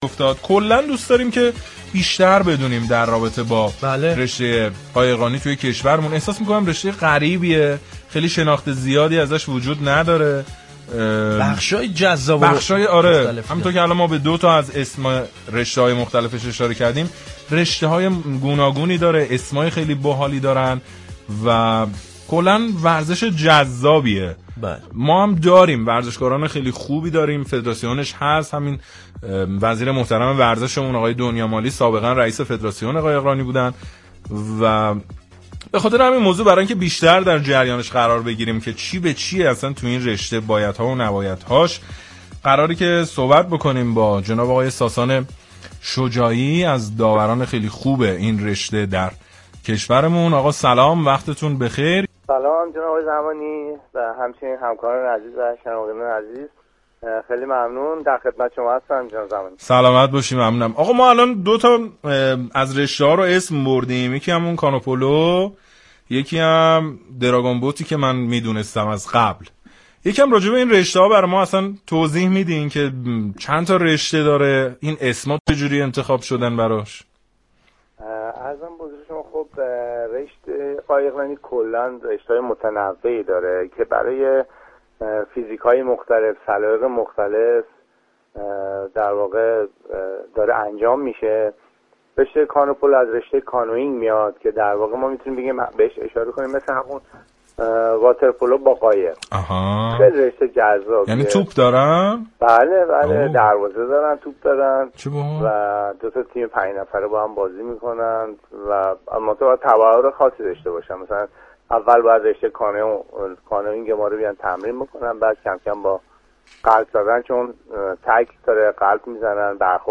به گزارش روابط عمومی رادیو صبا، «زیر طاقی» عنوان یكی از برنامه‌های ورزشی این شبكه رادیو است كه با رویكرد تازه به مرور اتفاقات ورزشی و حواشی آن در هفته‌ای كه گذشت می‌پردازد.